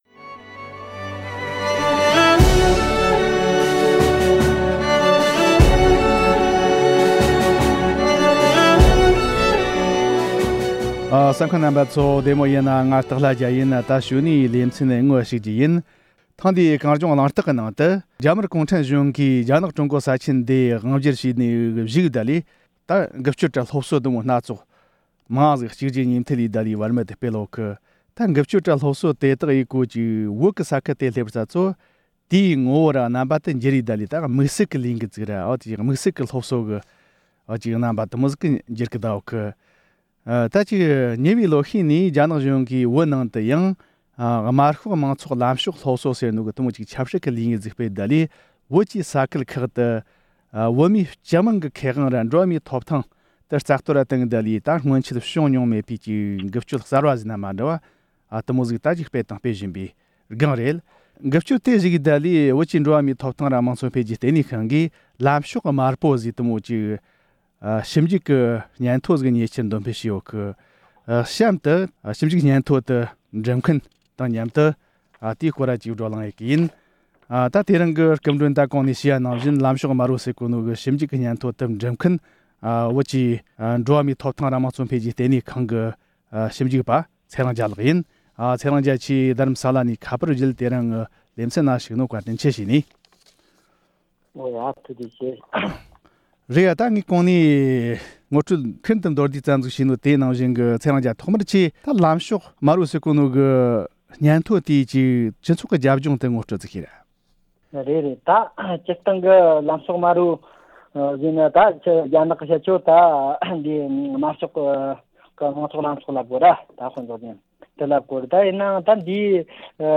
འབྲེལ་ཡོད་མི་སྣ་དང་ལྷན་དུ་བགྲོ་གླེང་ཞུས་པར་གསན་རོགས་ཞུ༎